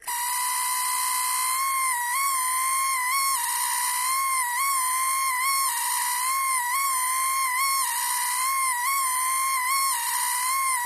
Screaming Bering Buzz